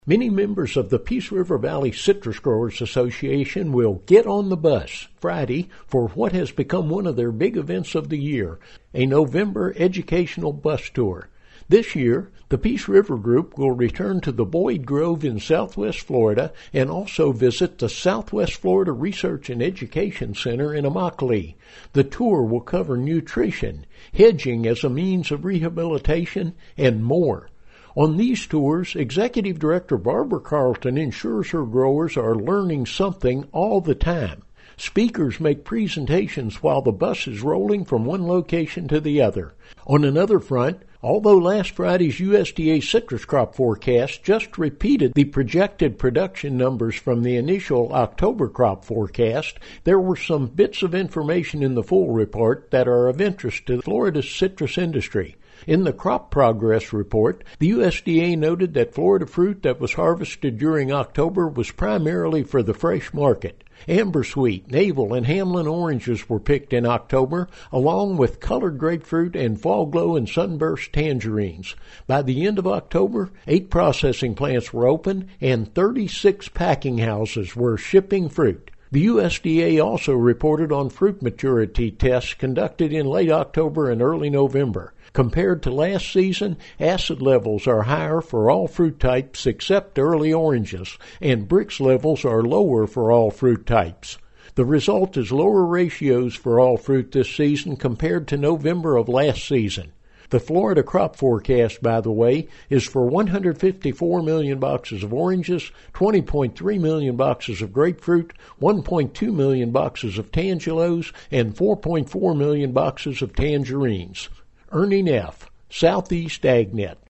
This report contains information on Peace River Valley Citrus Growers Association’s educational bus tour coming up Friday, and on the USDA’s latest crop forecast.